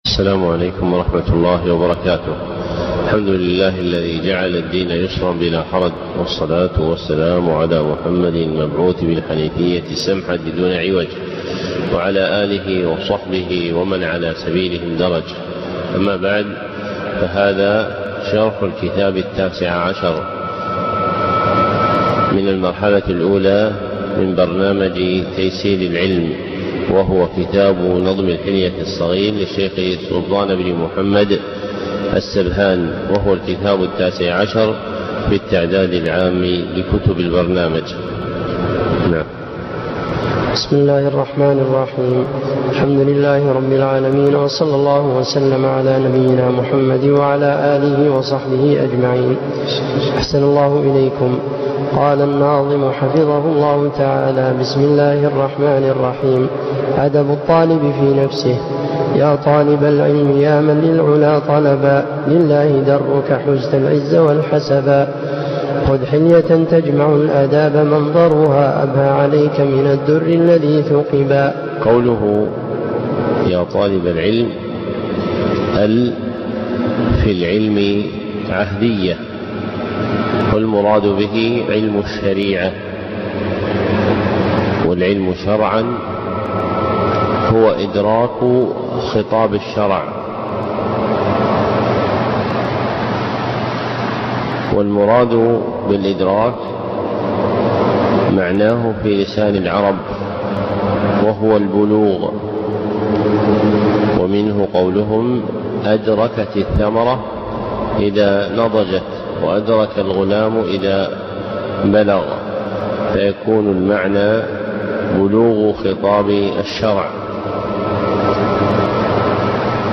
محاضرة صوتية نافعة